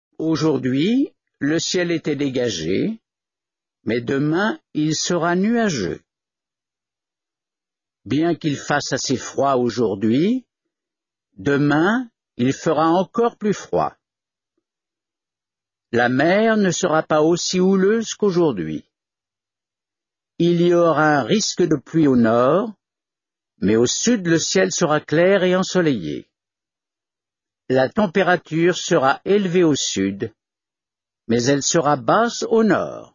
Course 1: Bulletin météo télévisé - Idea Learning